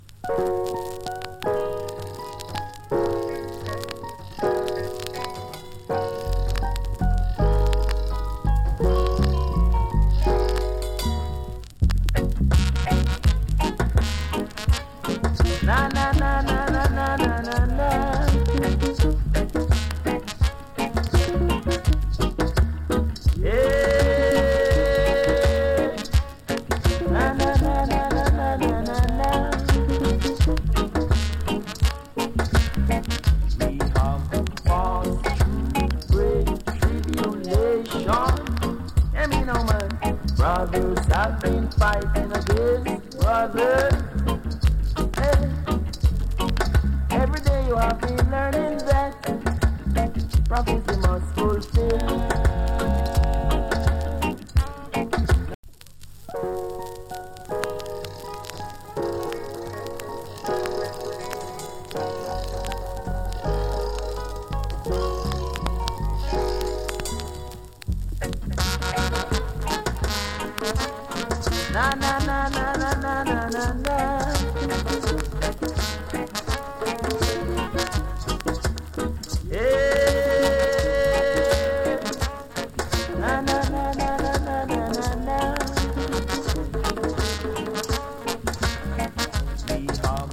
チリ、パチノイズ少々有り。
ROOTS ＋ DUB VERSION !!